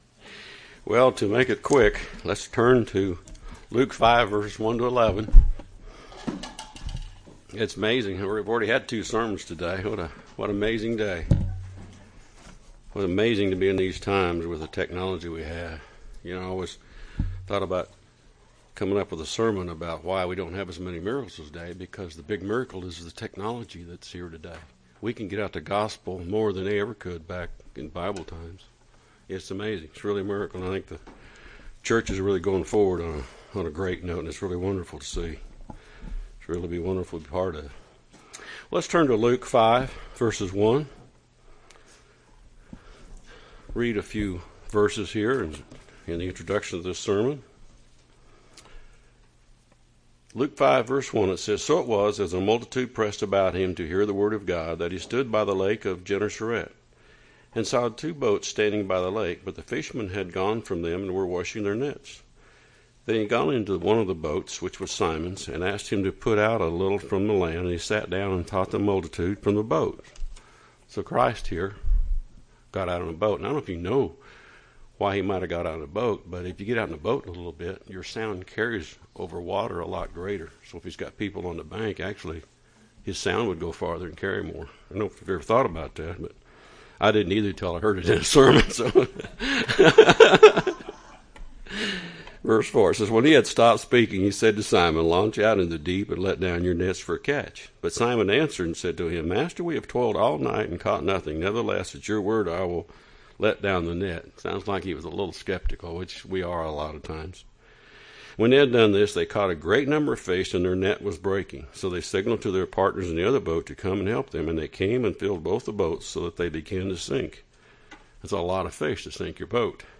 UCG Sermon Notes It's amazing that we've already had two sermons today.